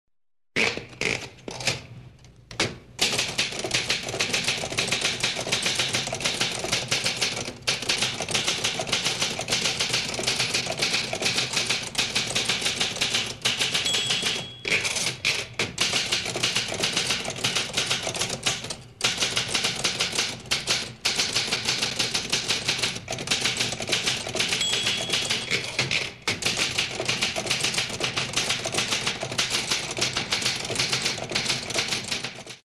Здесь собраны характерные эффекты: от ритмичного стука клавиш до металлического звона каретки.
8. Ускоренная печать текста